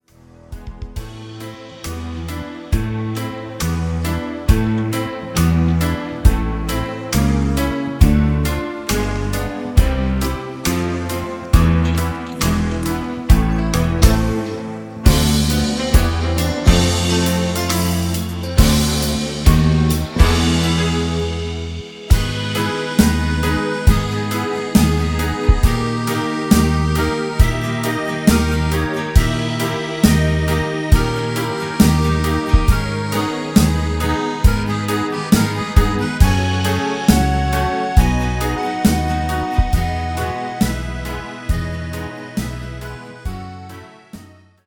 sans choeurs